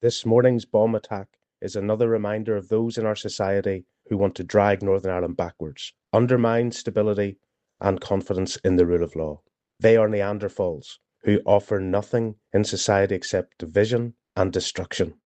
DUP leader Gavin Robinson has hit out at those responsible: